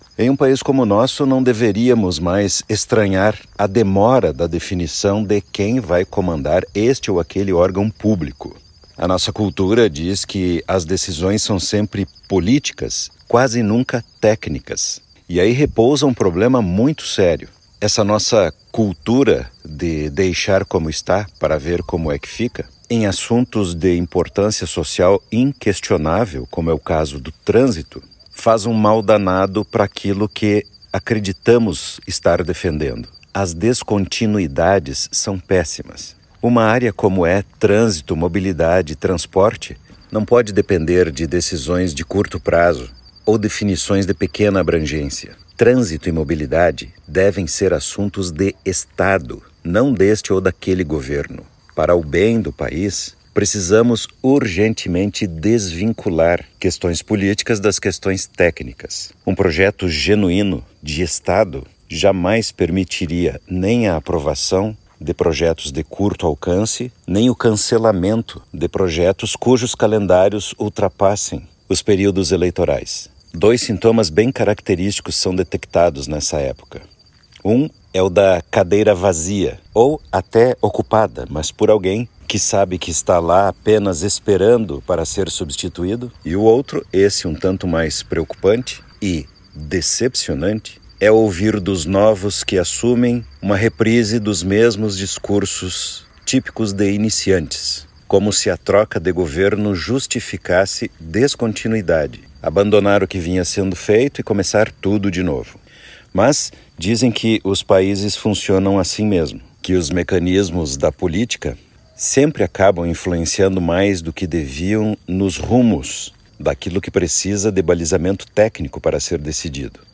Ouça o áudio completo do especialista: